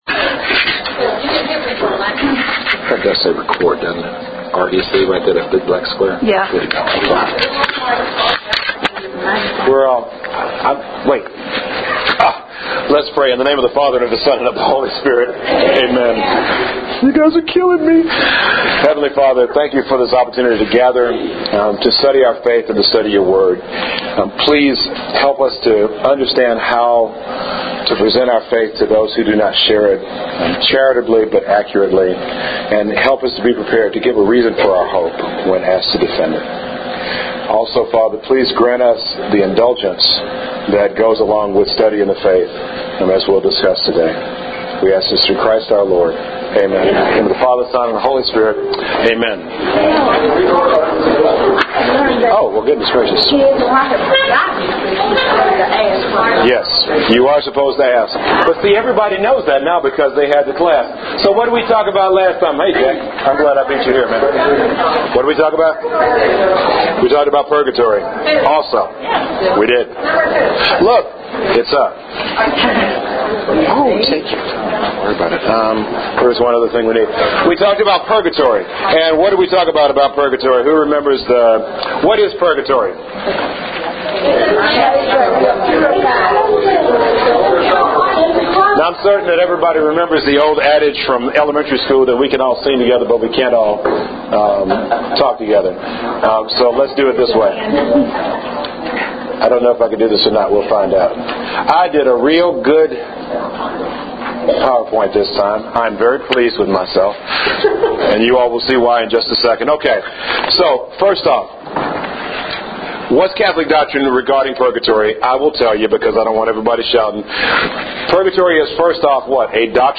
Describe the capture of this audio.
FIrst, THANK YOU, all of you, for a lively discussion about indulgences and Purgatory! Audio from the class is here.